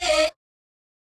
Vox (SlowMo).wav